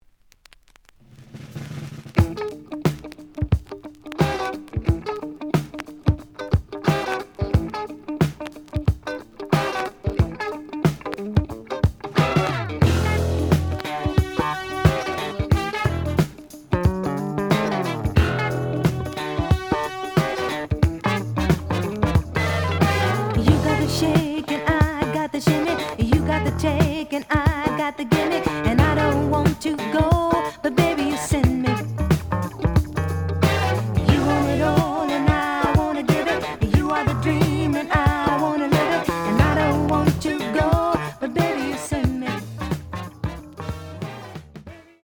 試聴は実際のレコードから録音しています。
●Genre: Disco